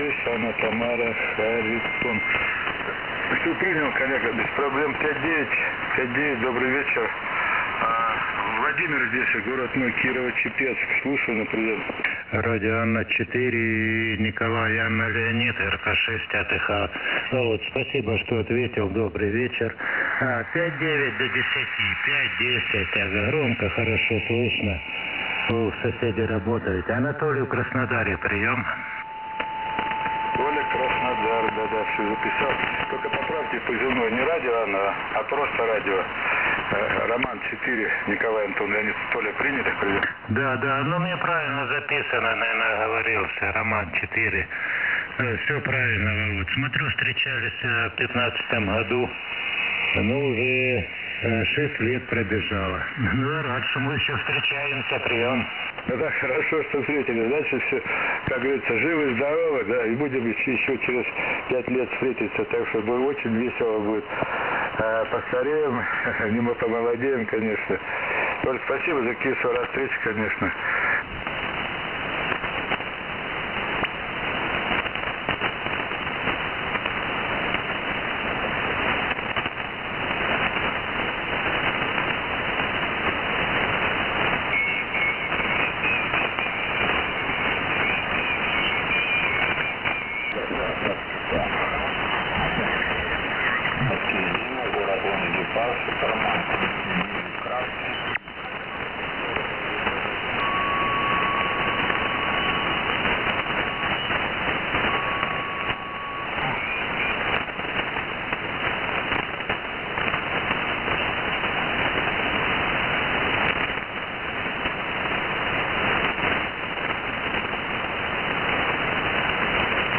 Я часто работаю с записями с радио эфира. Вопрос следующего плана в программе Izotope RX 8 какими средствами можно очистить звук оператора, убрать сам шум эфира, и другие артефакты.